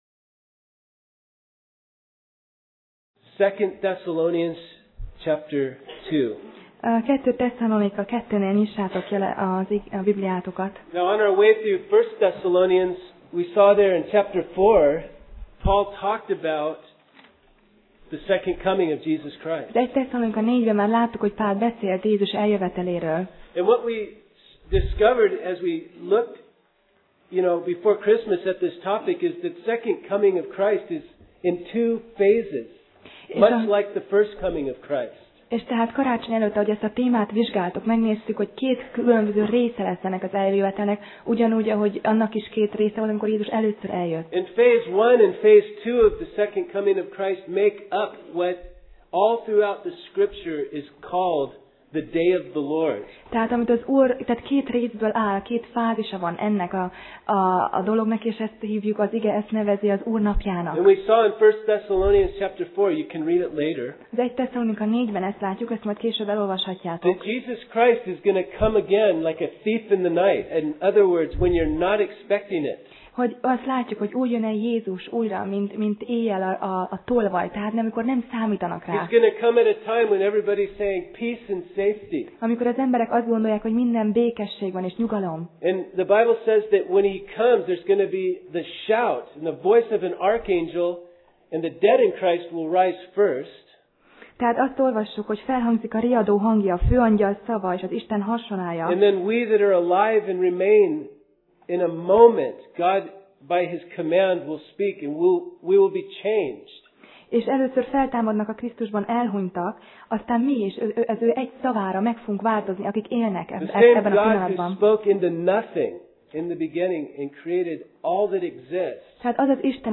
Alkalom: Vasárnap Reggel